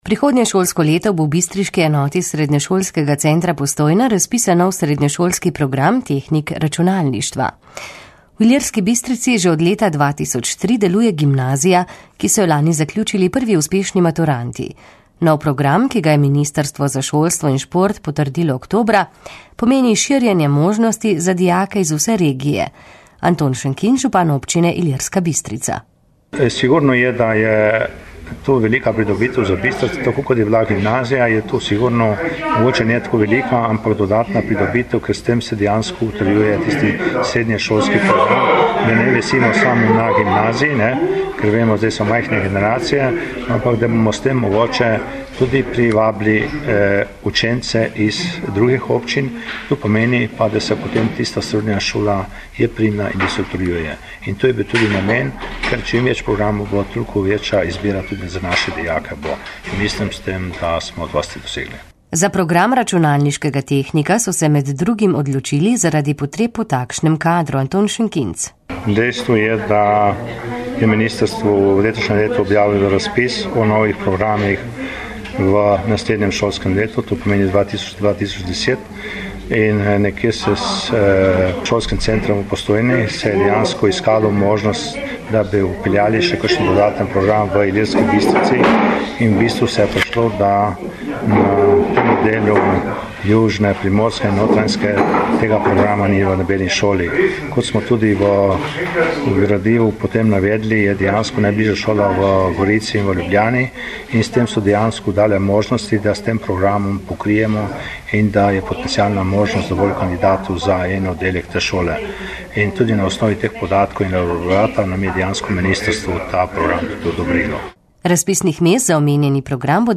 • novice radio94 r94